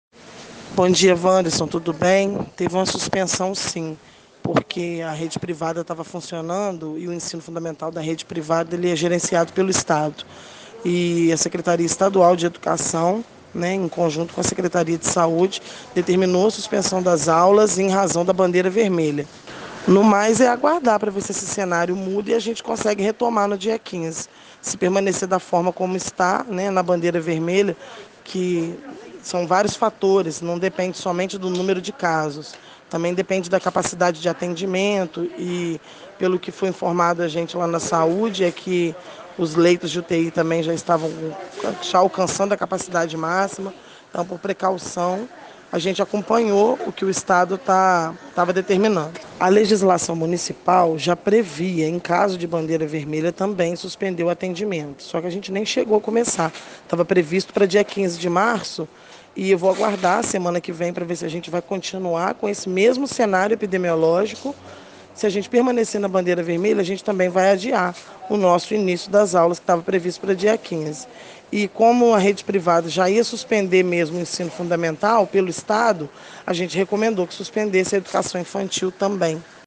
Por conta disso, as aulas presenciais na rede privada – educação infantil e fundamental – foram suspensas e a rede pública, que tinha previsão de retorno a partir da segunda quinzena de março, agora depende de novas avaliações. As informações foram confirmadas pela secretária municipal de educação Paula Ferreira